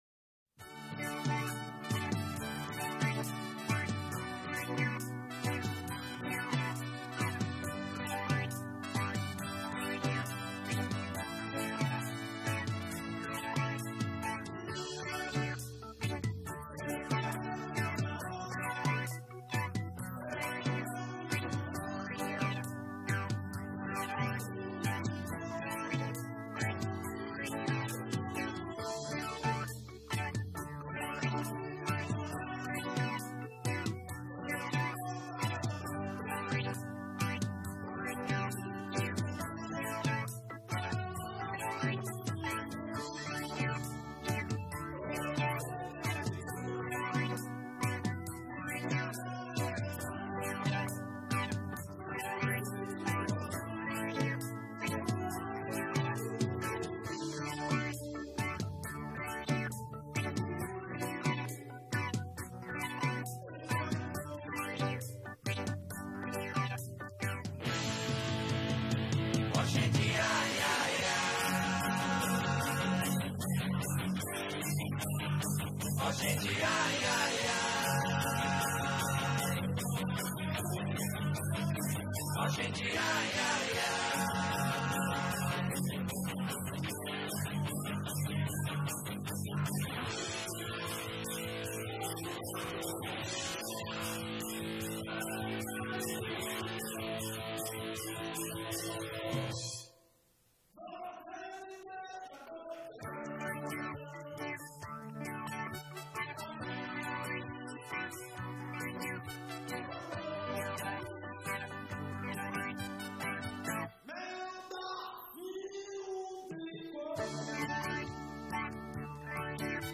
2024-02-18 19:42:16 Gênero: MPB Views